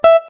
note_beepey_10.ogg